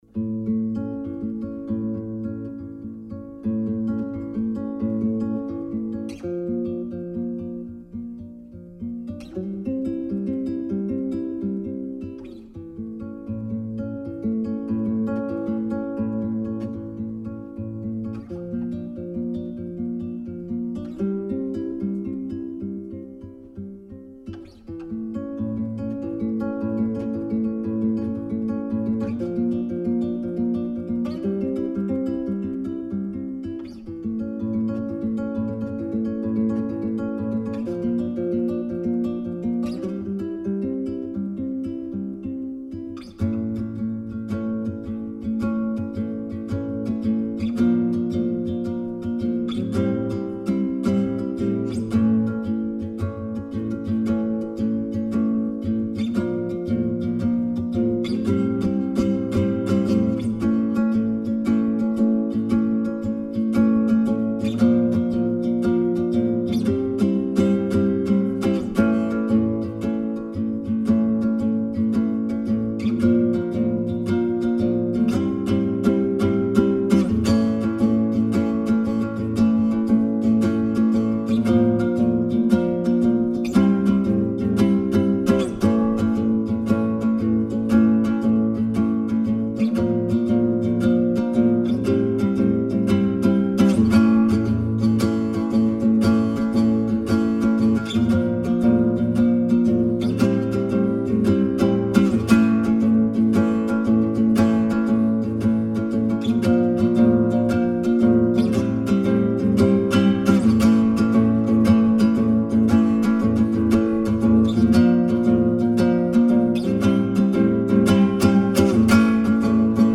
Tempo: 120 bpm / Datum: 29.01.2017